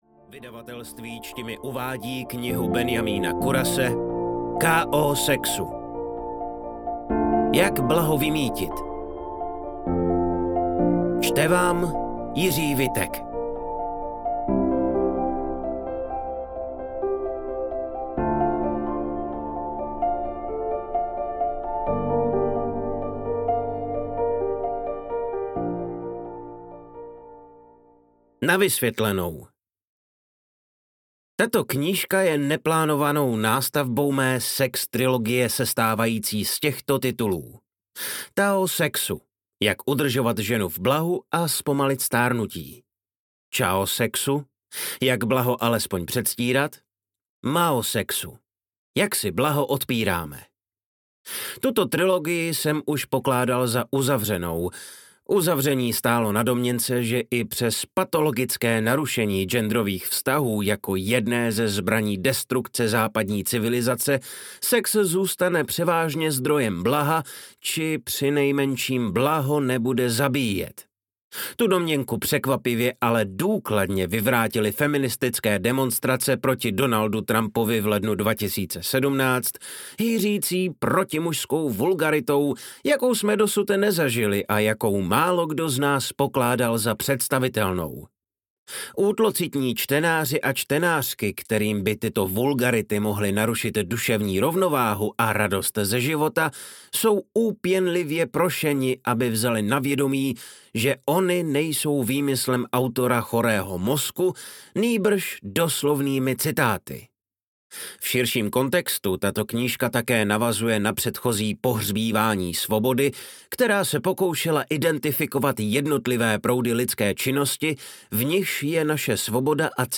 Ukázka z knihy
k-o-sexu-jak-blaho-vymytit-audiokniha